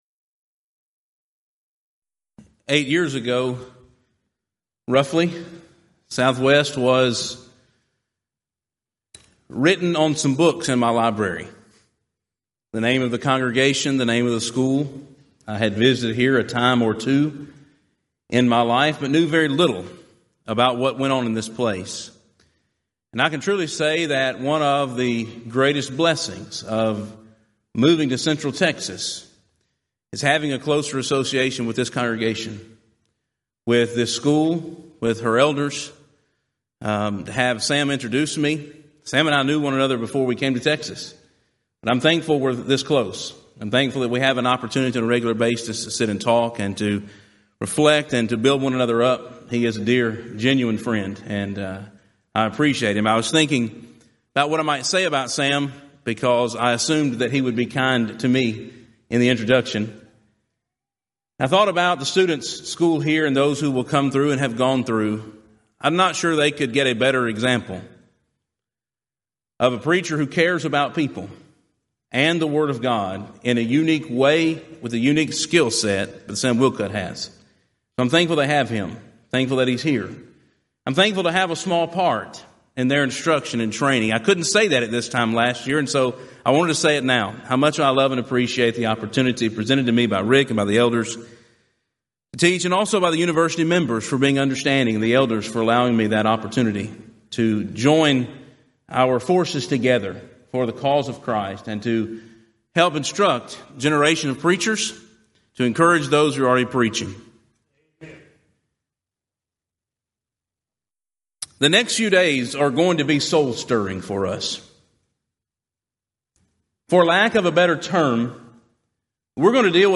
Event: 34th Annual Southwest Lectures Theme/Title: God's Help with Life's Struggles
lecture